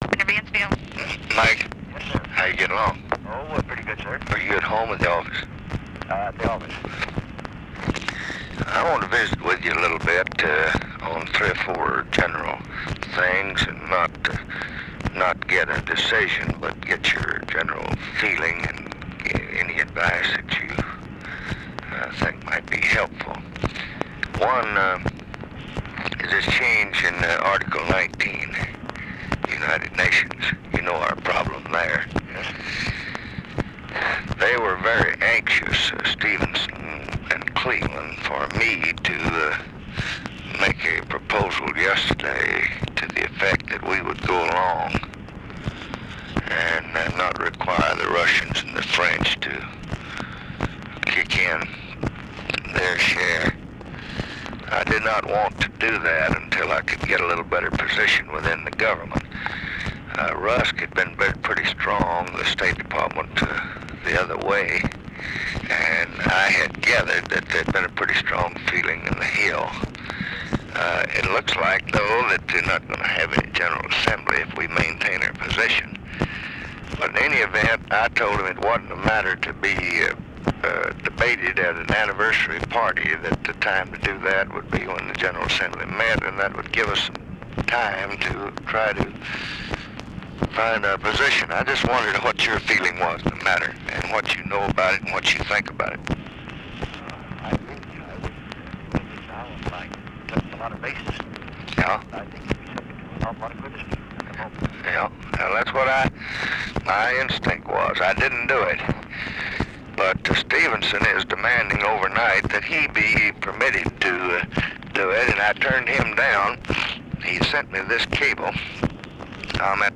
Conversation with MIKE MANSFIELD, June 26, 1965
Secret White House Tapes